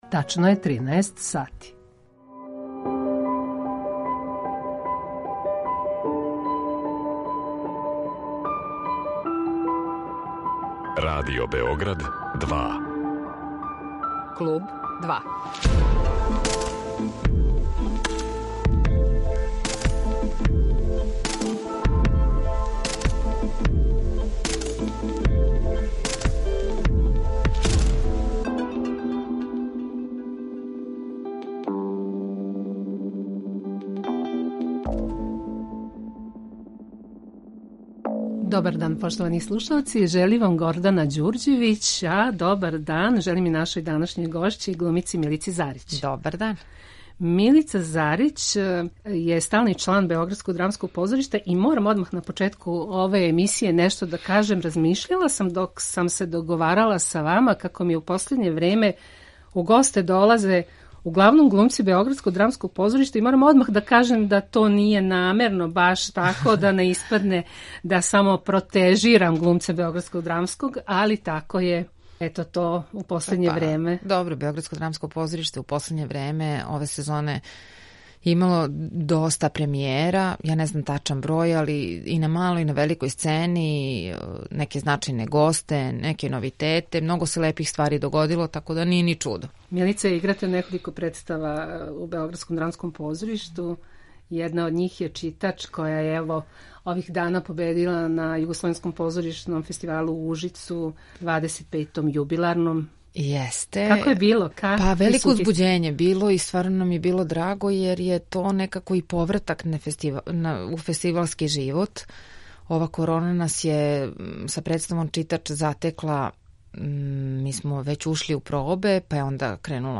Гост Клуба 2 је позоришна, телевизијска и филмска глумица Милица Зарић.